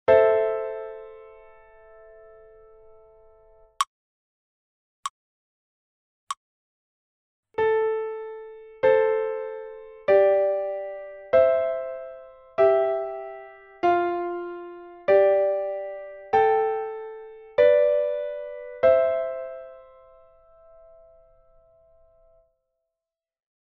Dvojhlasa_melodie_10_ACE_FAST.mp3